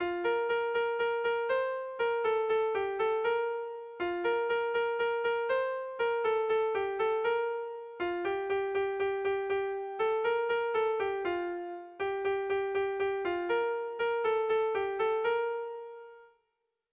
Air de bertsos - Voir fiche   Pour savoir plus sur cette section
Irrizkoa
Bizkaia < Euskal Herria
Seiko txikia (hg) / Hiru puntuko txikia (ip)
ABD